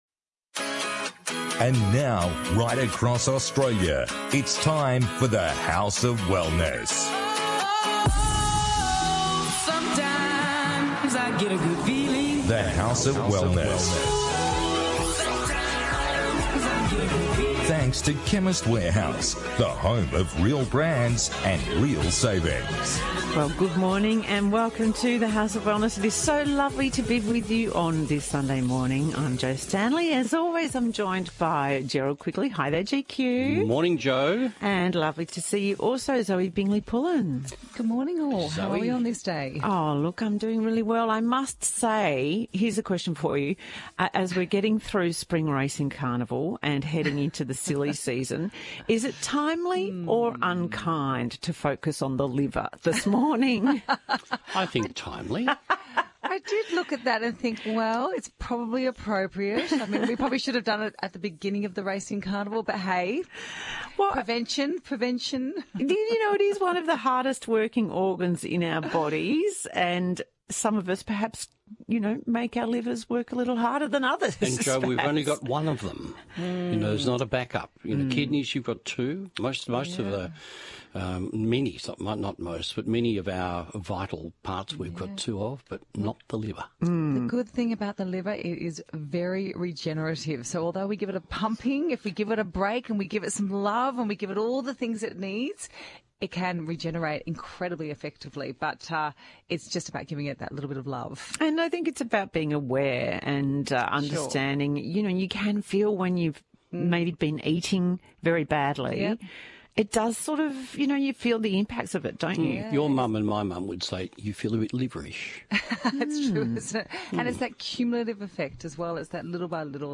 In this week’s The House of Wellness Radio episode the team discusses: